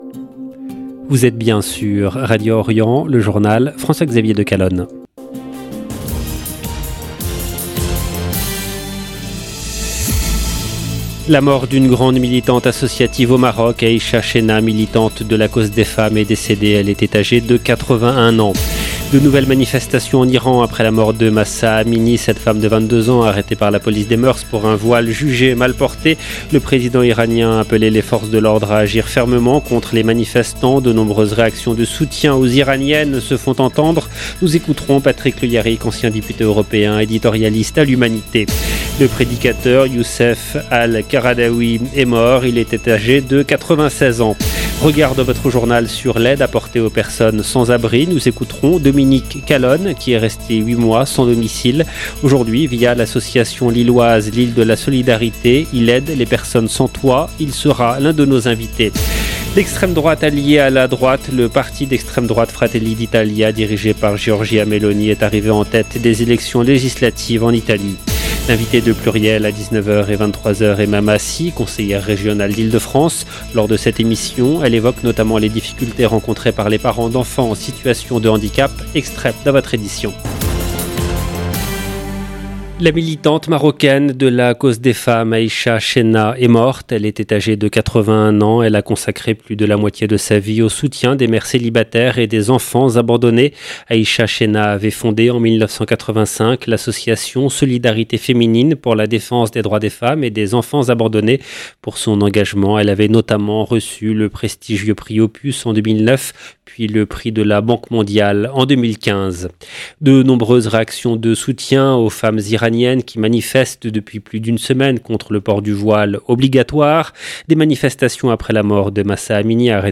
LE JOURNAL EN LANGUE FRANCAISE DU SOIR DU 26/09/22